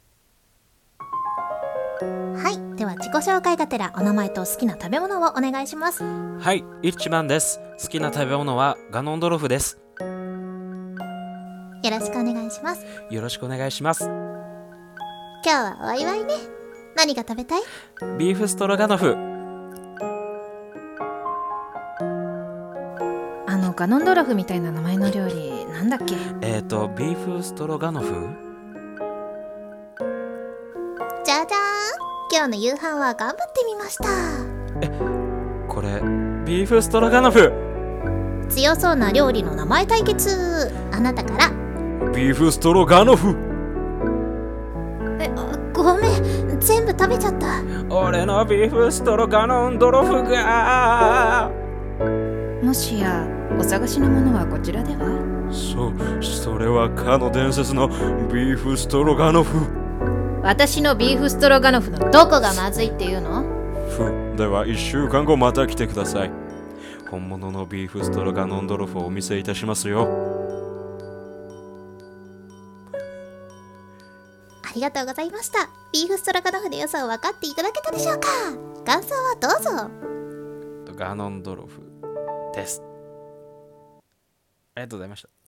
ビーフストロガノフ声面接？声劇？かけあい？？？